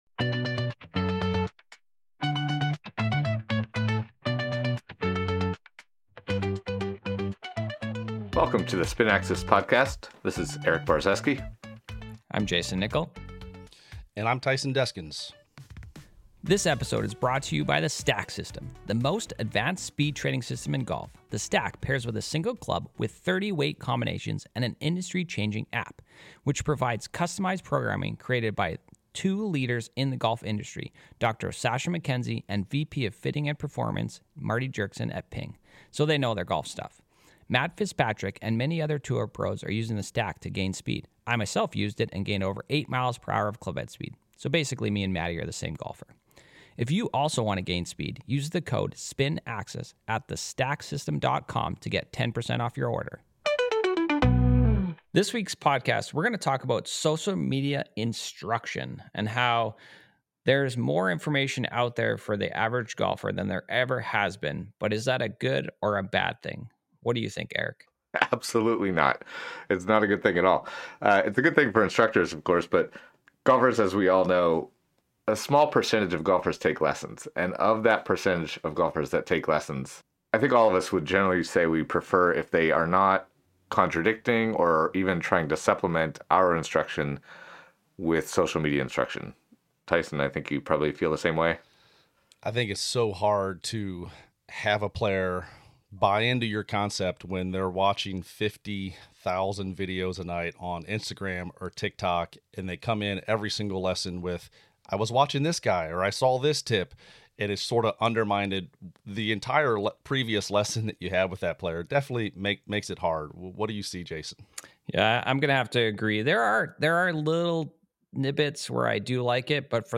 007: Social Media Instruction — Helpful Hack or Harmful Shortcut? | The Spin Axis Podcast - Golf Coaches Discuss Instruction Modern Techniques and Technology